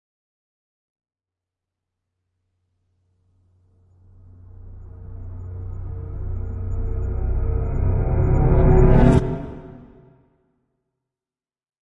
Scary Tension Buildup 02 Sound Effect Download: Instant Soundboard Button